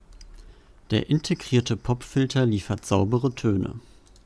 Und hier ist eine Aufnahme mit ausreichendem Sitzabstand.